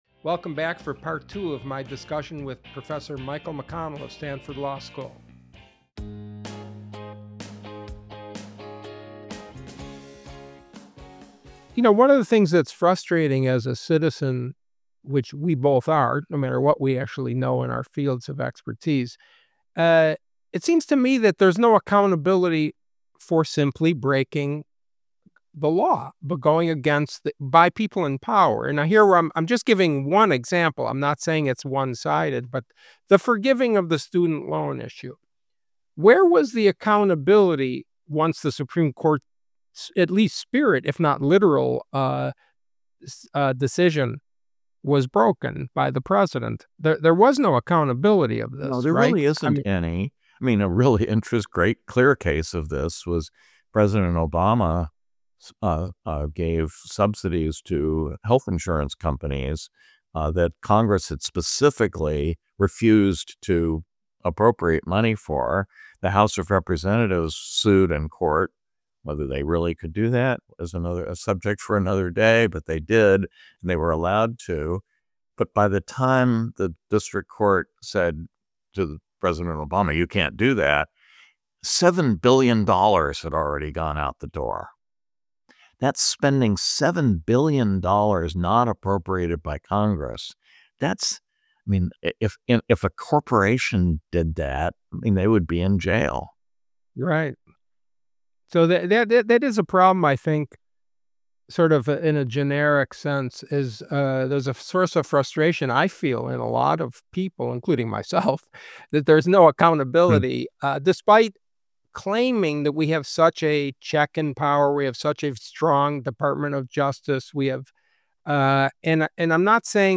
In the second part of this two part episode, Scott welcomes H. R. McMaster, former Trump administration National Security Advisor to the show. They have a conversation about several of today's hotspots in the world including ongoing military battles arising under the Biden Administration and how our elected leaders are functioning to keep us safe.